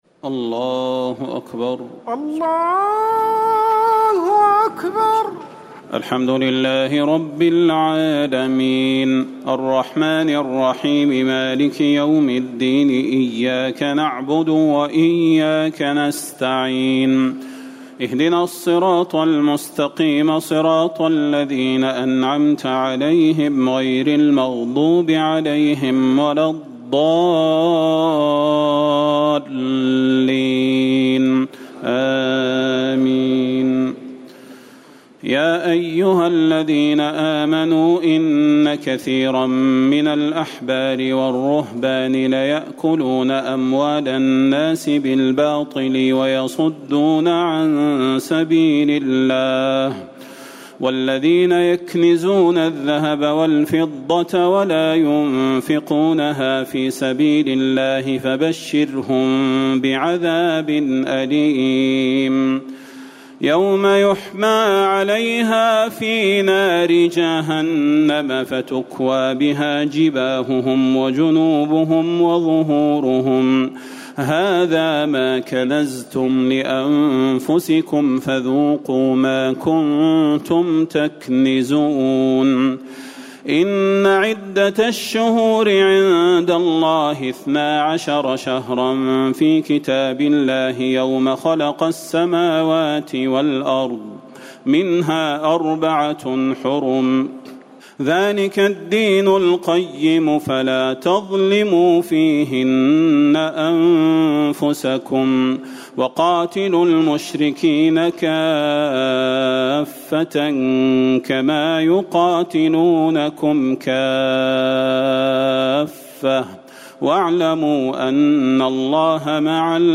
تراويح الليلة التاسعة رمضان 1439هـ من سورة التوبة (34-93) Taraweeh 9 st night Ramadan 1439H from Surah At-Tawba > تراويح الحرم النبوي عام 1439 🕌 > التراويح - تلاوات الحرمين